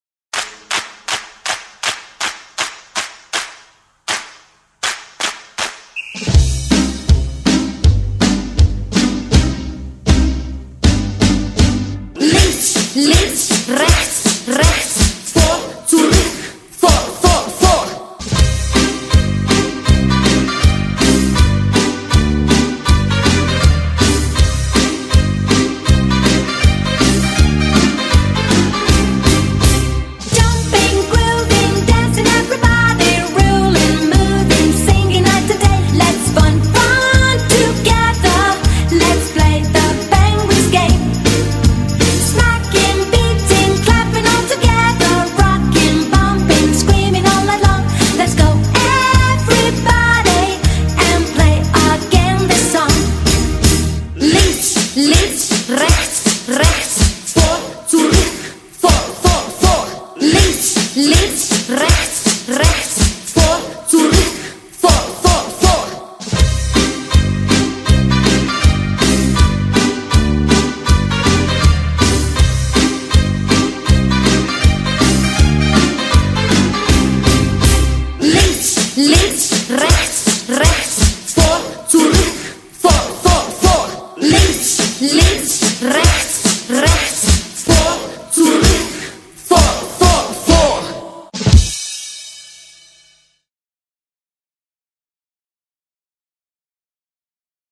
BPM160--1
Audio QualityPerfect (High Quality)